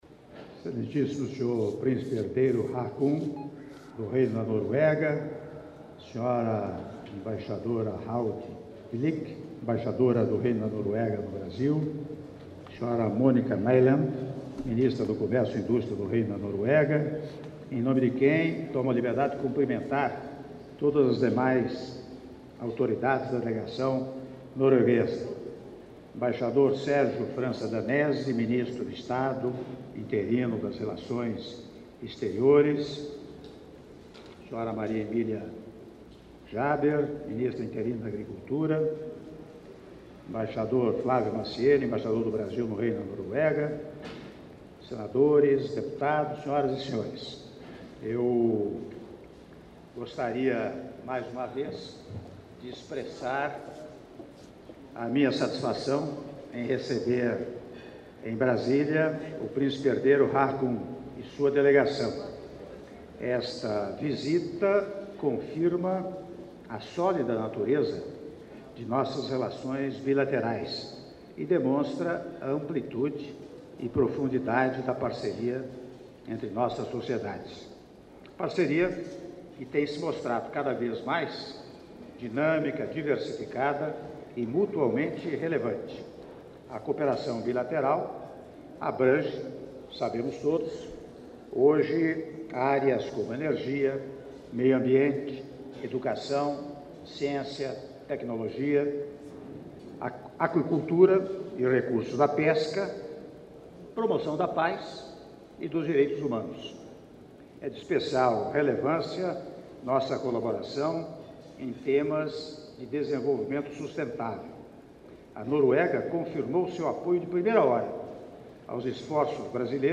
Áudio do brinde do Presidente da República em exercício, Michel Temer, durante almoço em homenagem ao Príncipe Herdeiro da Noruega, Haakon Magnus - Brasília/DF (06min27s)